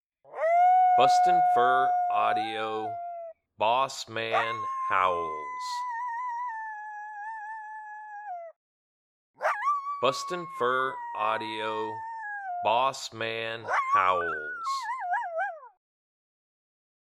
BFA Bossman Howls
BFA's Alpha Male Apollo, Lone Howling
BFA Bossman Howls Sample.mp3